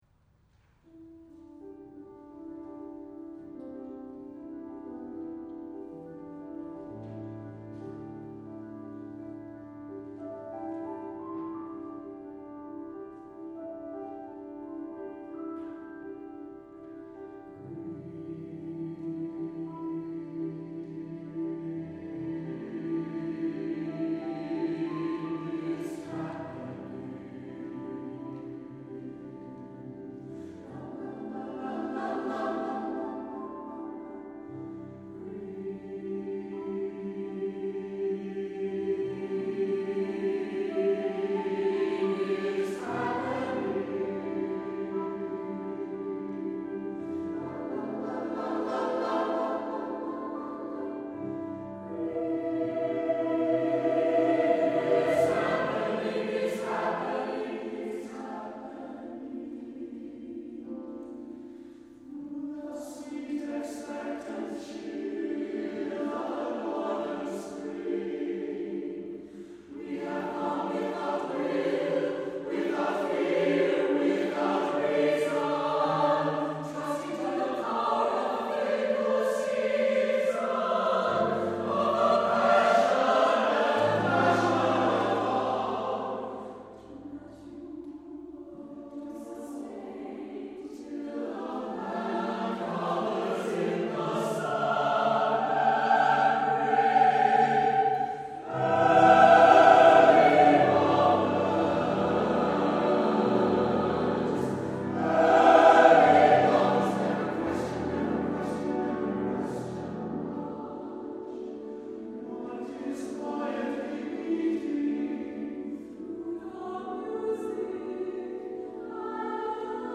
for SATB Chorus and Piano (1998)